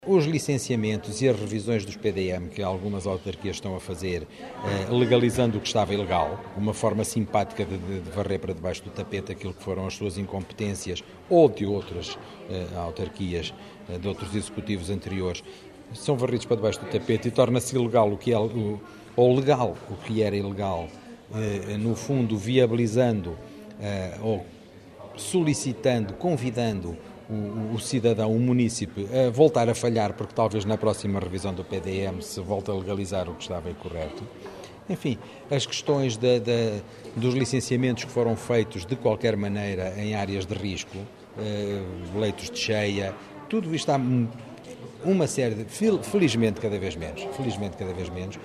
Declarações à margem do 7º Encontro de Boas práticas ambientais que terminou ontem e durante dois dias reuniu especialistas nacionais e internacionais na à área do ambiente, no Instituto politécnico de Bragança.